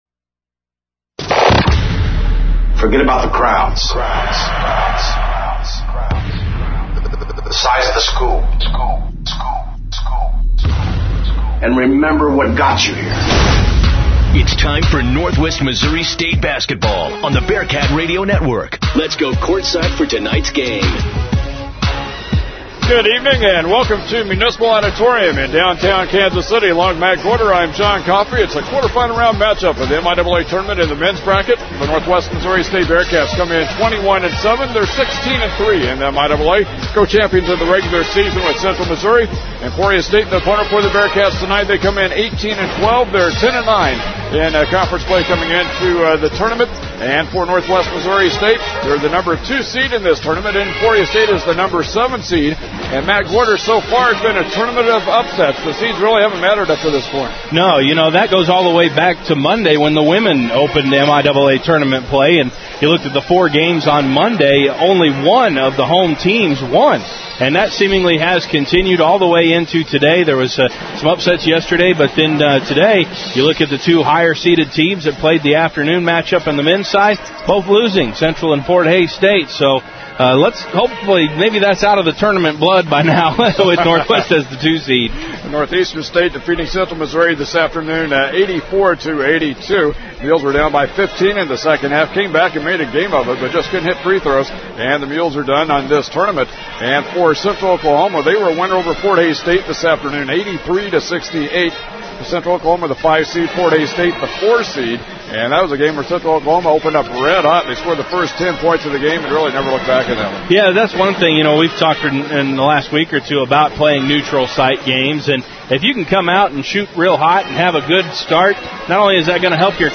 Broadcasts | Bearcat Radio Network | KXCV-KRNW